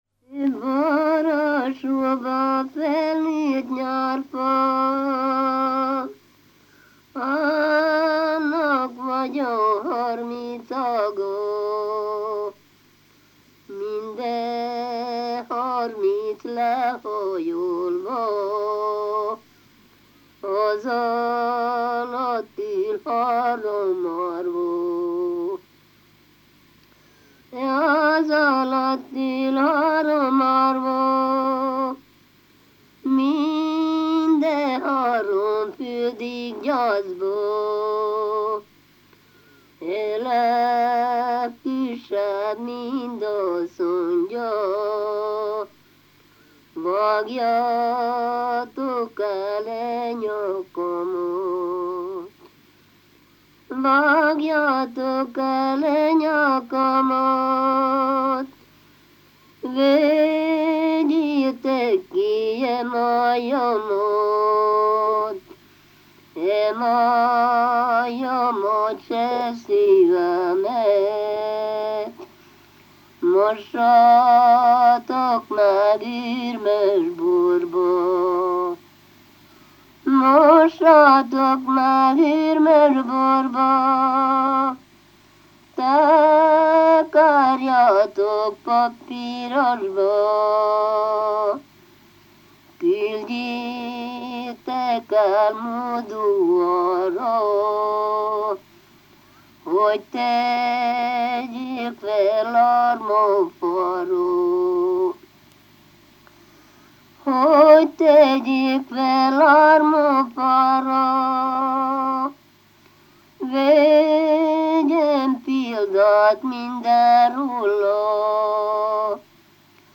ének
ballada
Klézse
Moldva (Moldva és Bukovina)